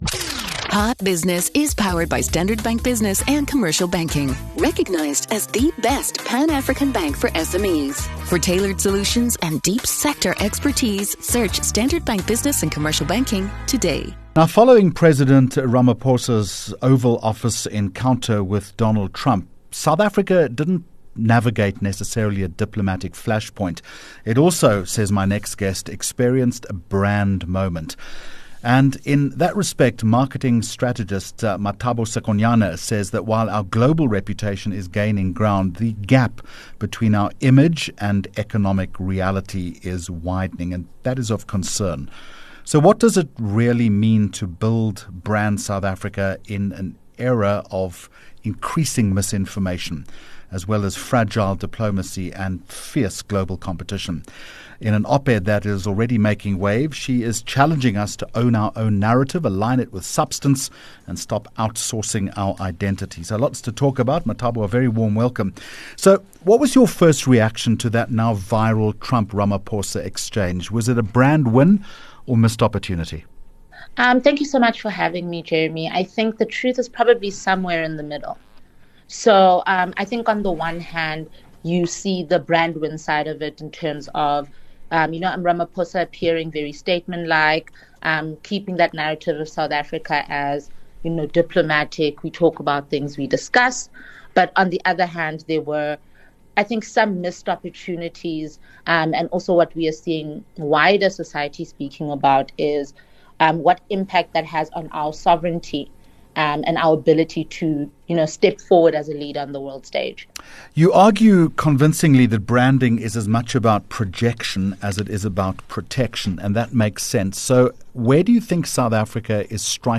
2 Jun Hot Business Interview